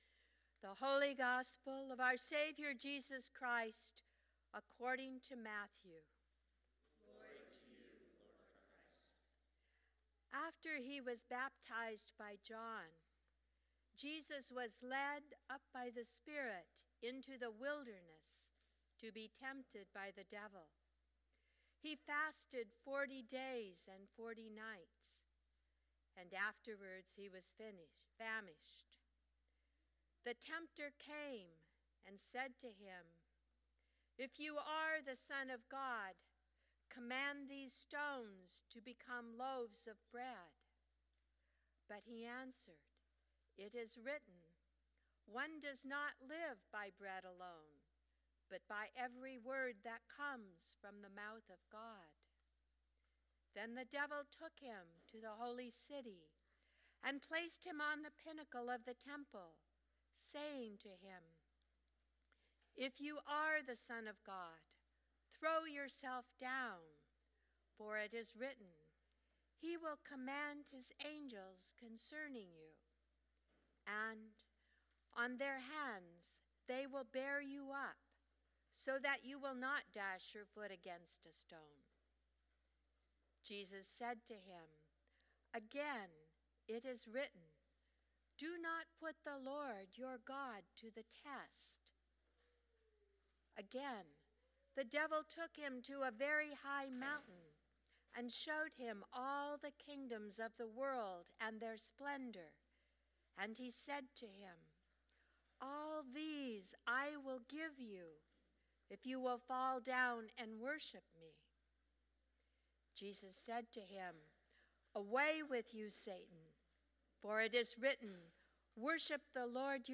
Gospel Reading: Matthew 4:1-11